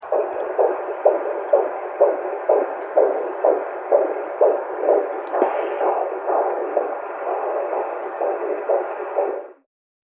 Коллекция включает разные варианты записей, от монотонных сигналов до динамичных изменений.
Мониторинг сердцебиения плода при беременности с помощью ультразвукового исследования